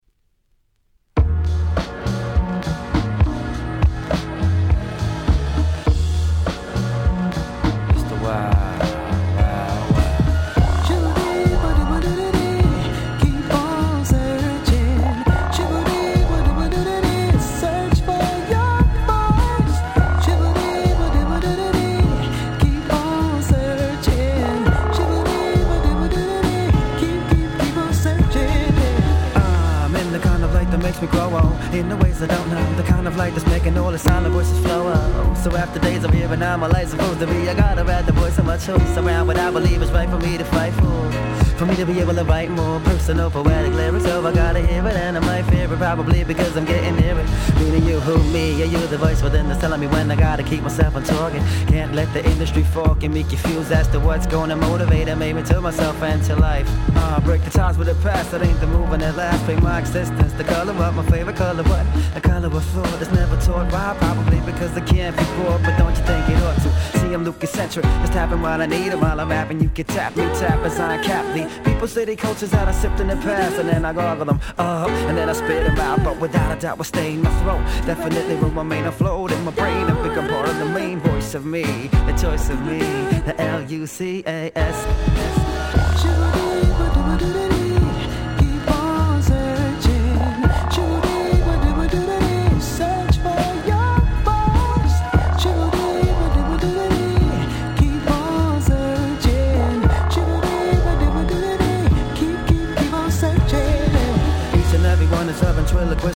94' Nice Hip Hop !!